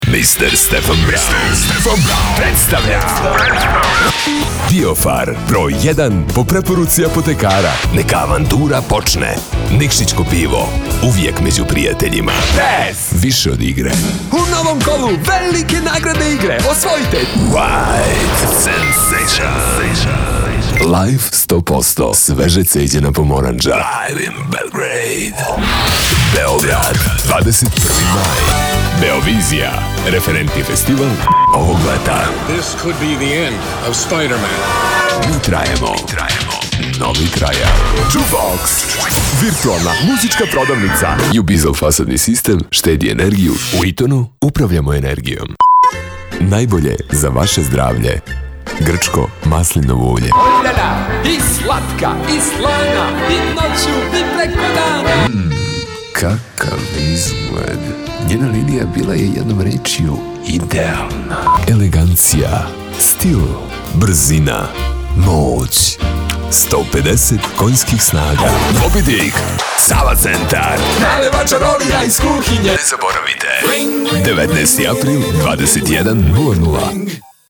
Native speaker Male 30-50 lat
Low, soft voice.
Nagranie lektorskie